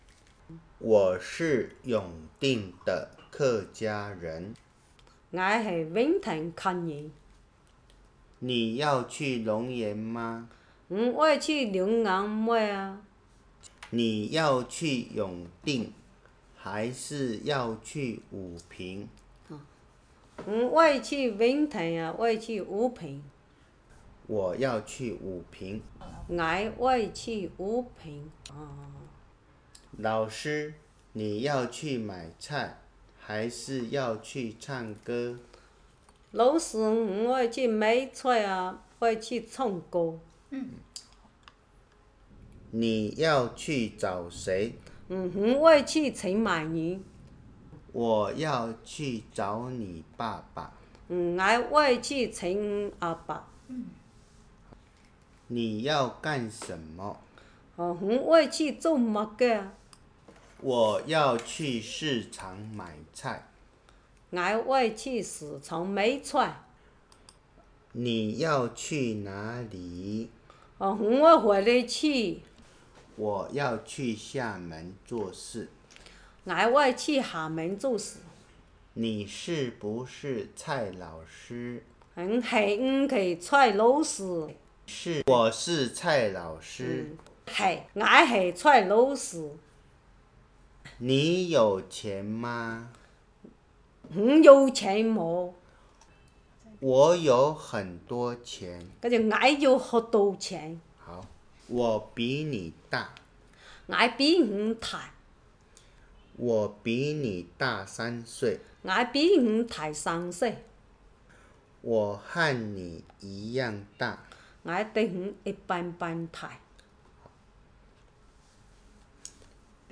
永定饒平腔-語法例句 | 新北市客家文化典藏資料庫